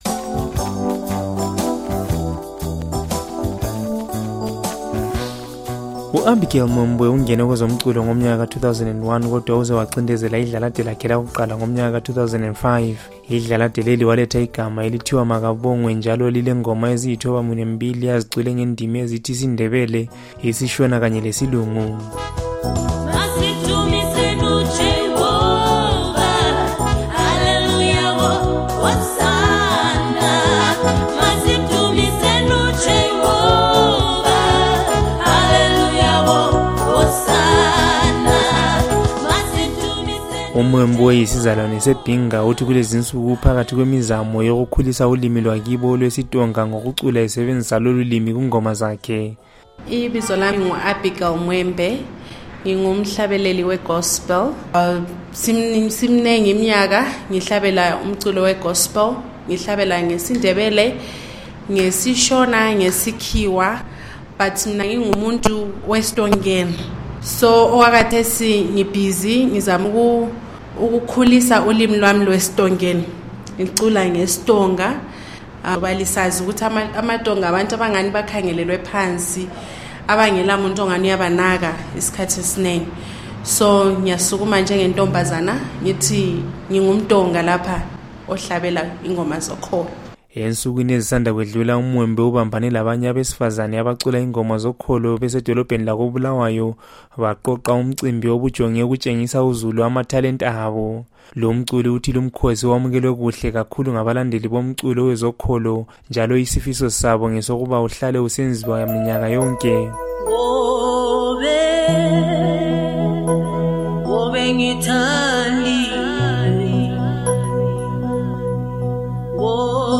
usethulela ingxoxo ayenze lomculi wengoma zokholo